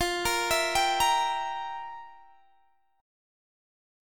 Listen to FM7sus2sus4 strummed